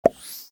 chat-message.mp3